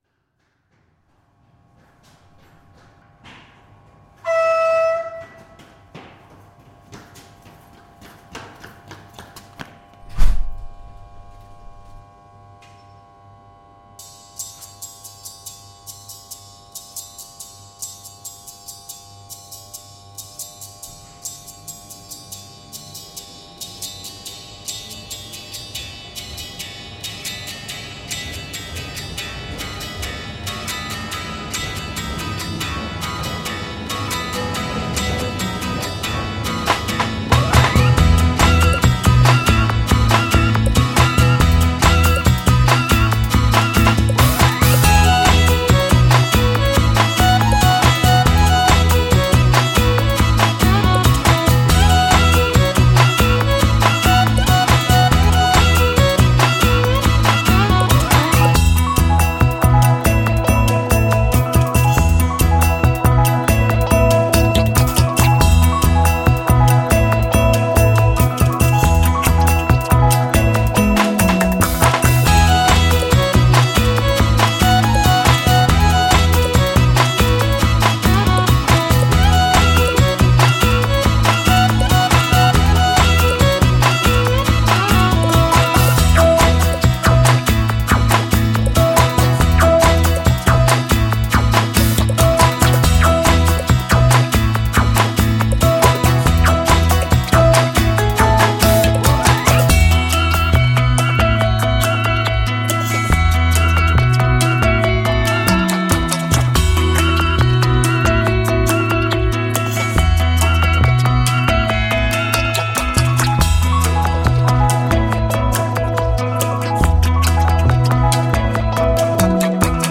Electro-Acoustic